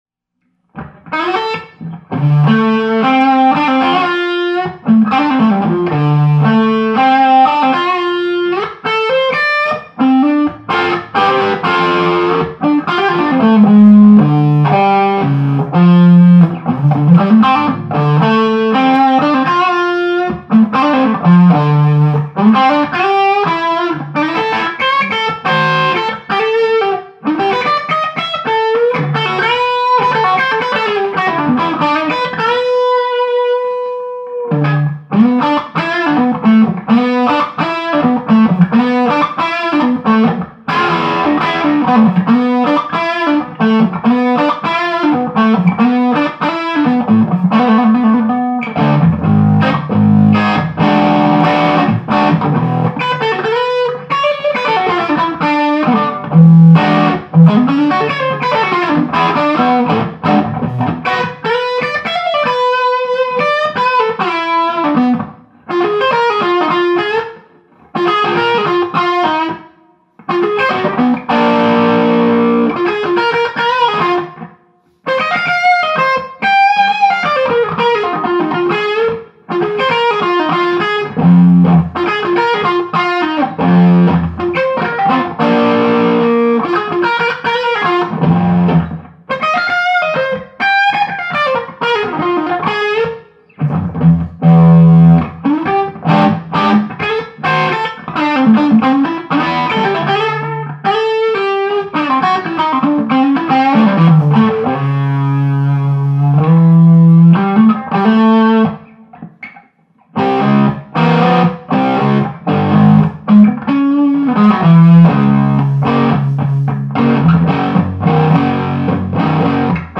Recording is just a hand held Sony PCM D50 digital flash recorder a bit off axis.
Glaswerks 2X12 cab with new Heritage G1265 speakers.
We also recorded a clip of me Fuchs 75 mod with the same exact circuit. It is basically rewired and has a built in Dumbleator.
However, my loop has cable simulation designed in.
The biggest difference is in how the different trannies saturate and the basic tonality difference of the 6L6 tubes since one runs them at 670 or so.
Fuchs is almost dull sounding compared to Buldo.